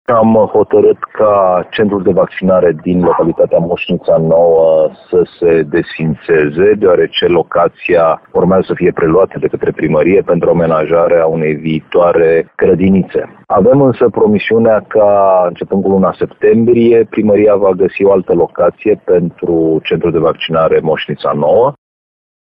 Decizia de închidere a centrului din Moșnița Nouă a fost luată de Nucleul Județean de Vaccinare, spune subprefectul Ovidiu Draganescu.